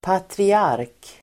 Uttal: [patri'ar:k]